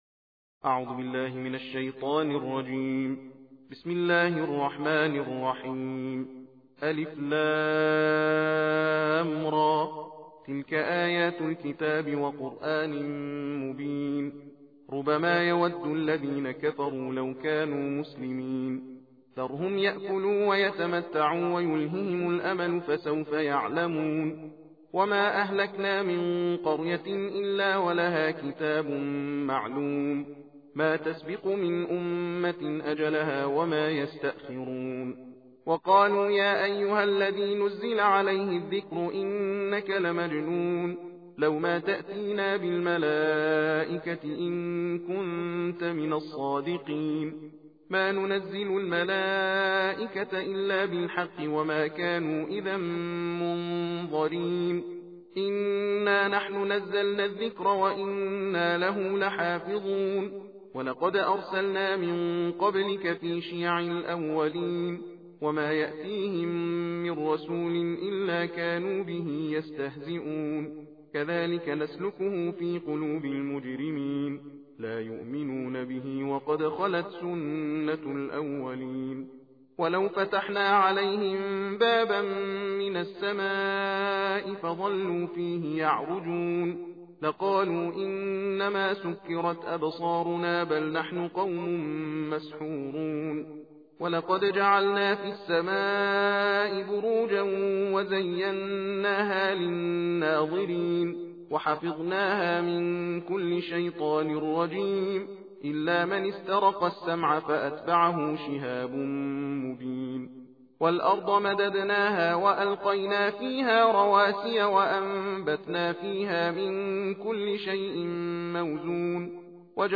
ترتیل جزء چهاردهم قرآن کریم را در ادامه به همراه متن و ترجمه می توانید دریافت نمایید.